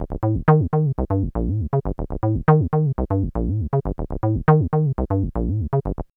BASS_L_7.wav